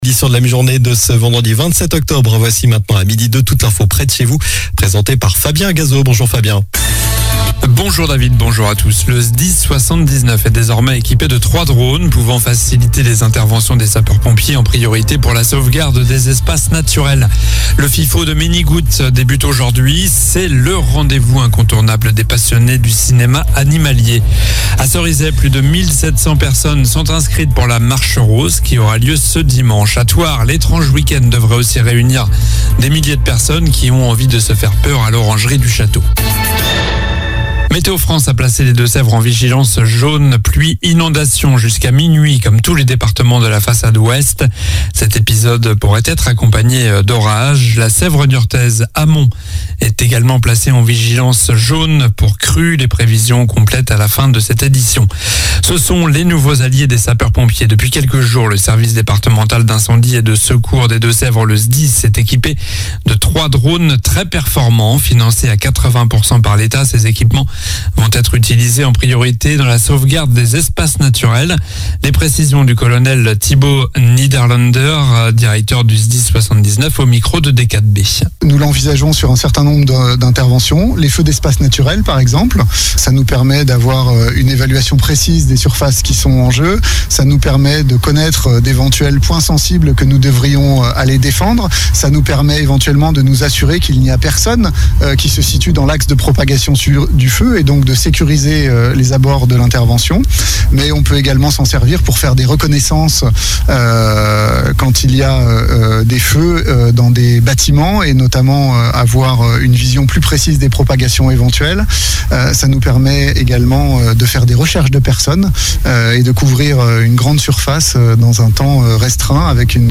Journal du vendredi 27 octobre (midi)